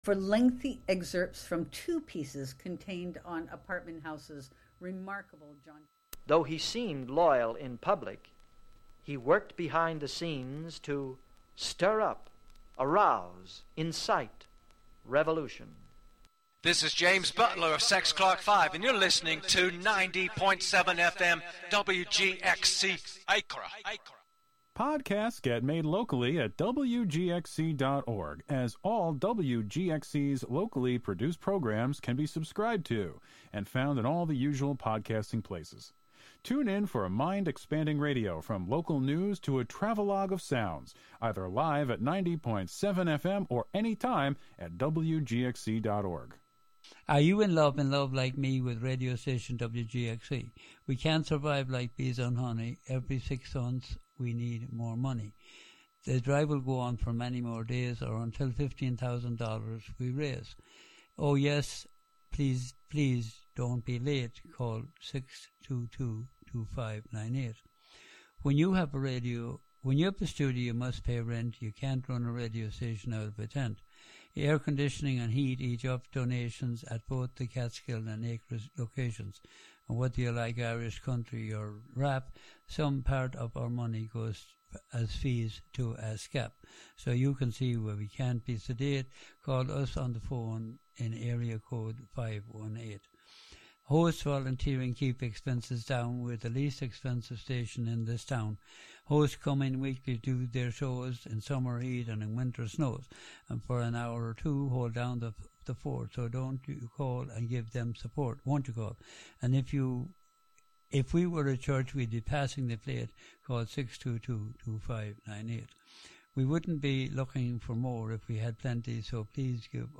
This hour is here to remind you that we are all the Grandmasters of our own lives. Be prepared to fly through various experiences on the air waves where you may hear any and everything...